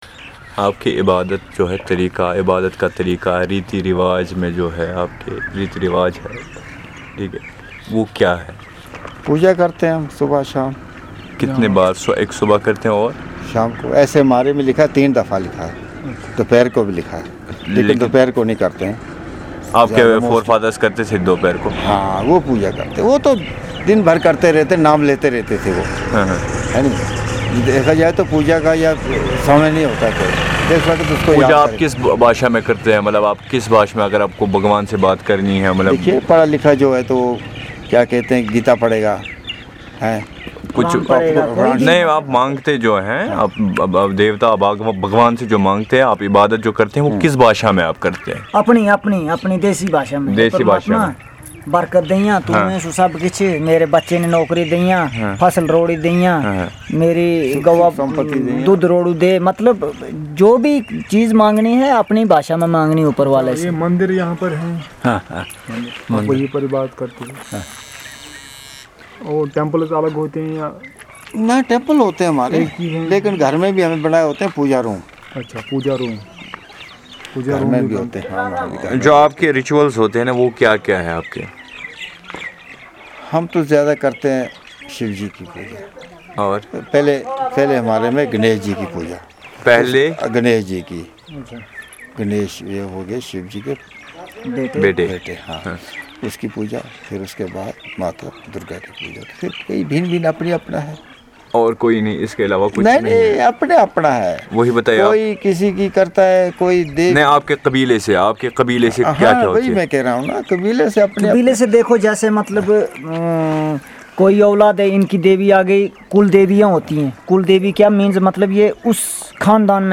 NotesThis is an oral narrative on the the religion, customs and traditions of the worship.